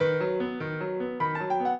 piano
minuet8-8.wav